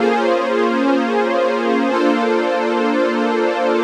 cch_synth_loop_frankie_125_Bb.wav